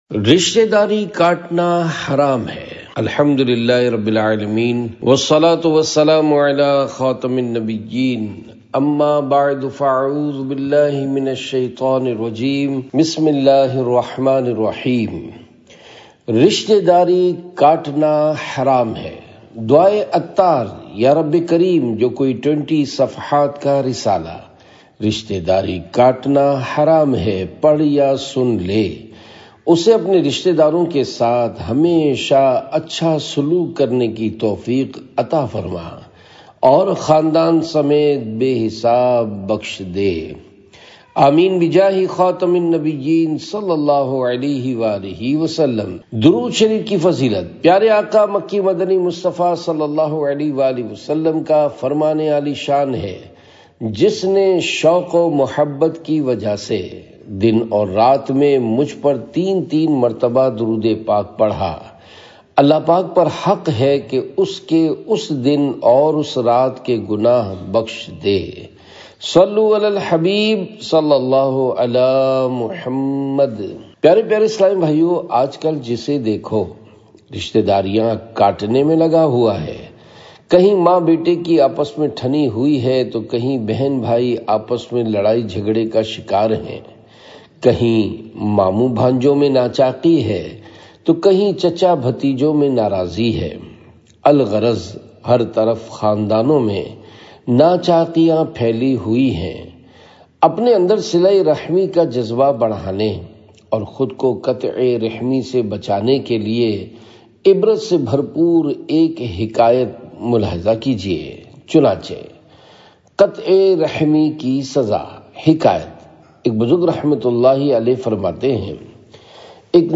Audiobook - Rishtedari Katna Haram Hai (Urdu)